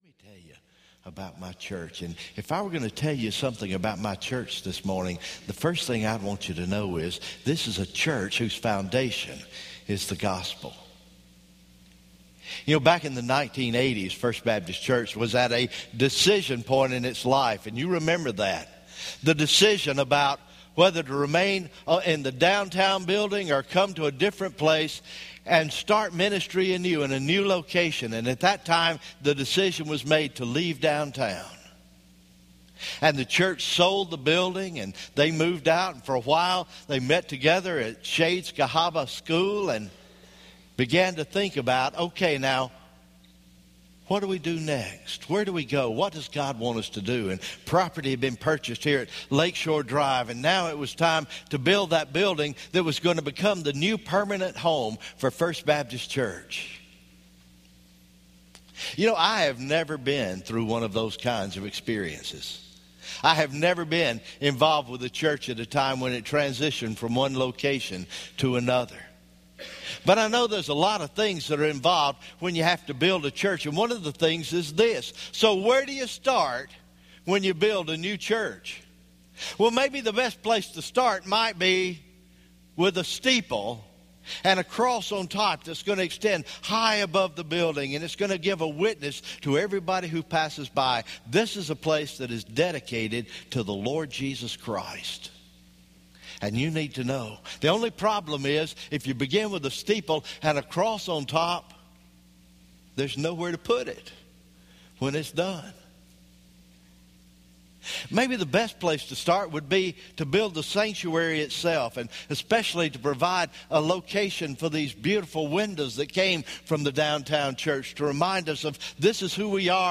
January 22, 2017 Morning Worship